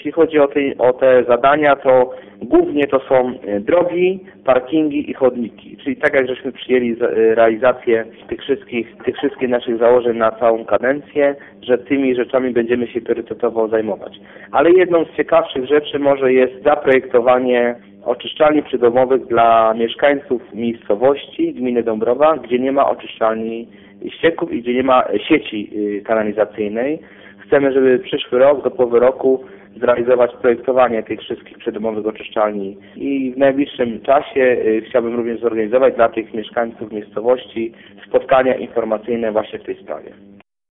Wśród 42 inwestycji jakie mają zostać zrealizowane w przyszłym roku, dużą część środków władze postanowiły przeznaczyć na modernizację dróg. Jednak jednym z ważniejszych zadań ma być wykonanie projektu przydomowych oczyszczalni ścieków, mówi wójt Marcin Barczykowski.